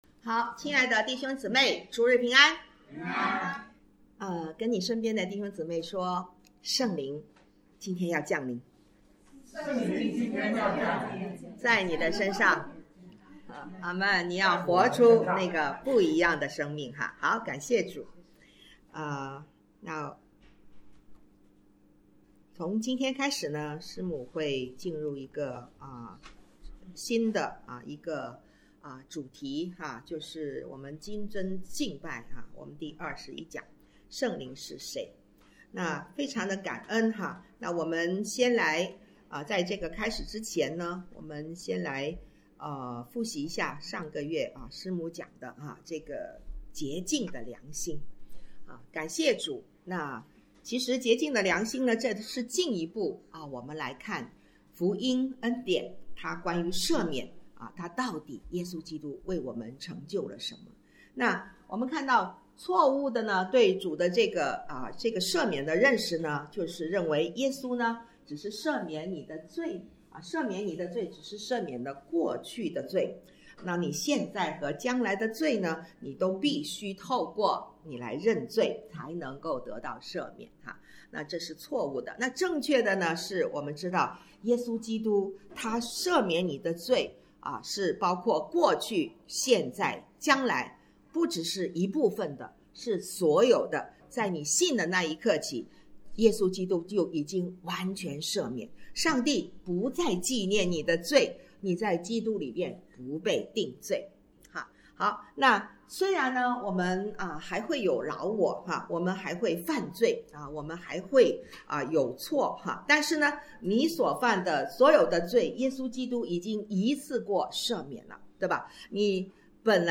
讲道录音 点击音频媒体前面的小三角“►”就可以播放 了。